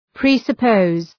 Προφορά
{,pri:sə’pəʋz}